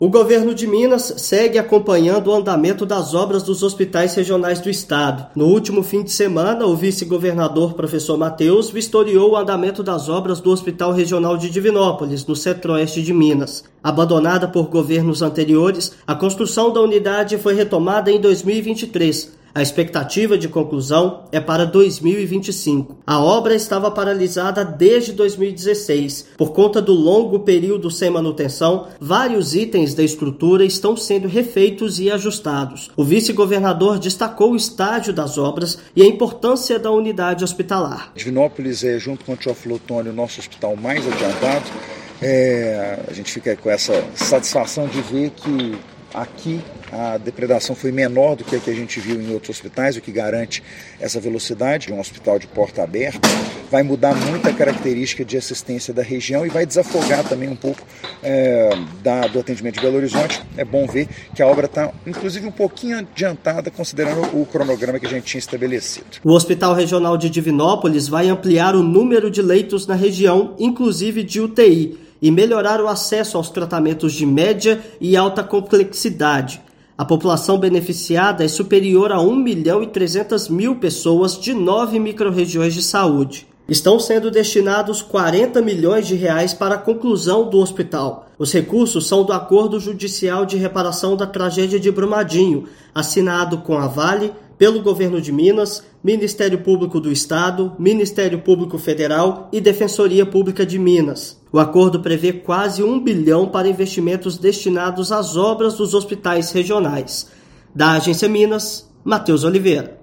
Previsão é que a unidade hospitalar esteja pronta para atendimento na região Oeste no segundo semestre de 2025. Ouça matéria de rádio.